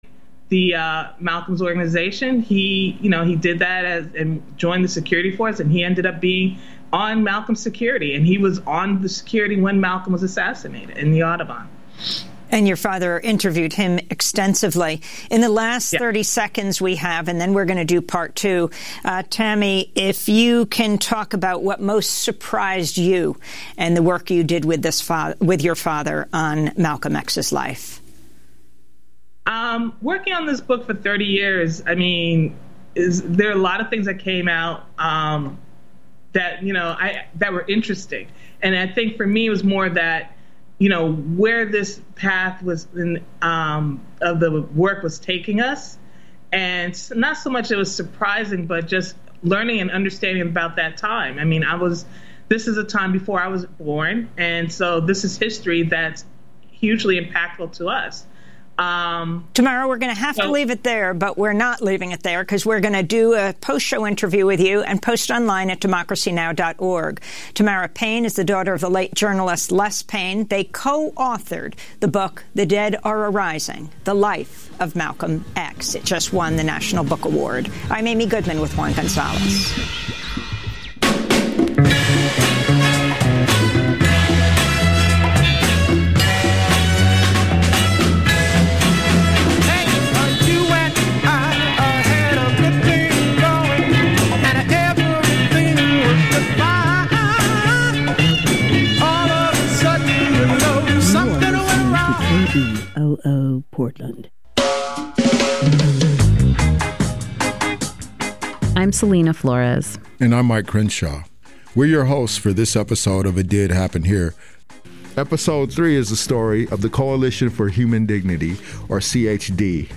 Evening News on 12/02/20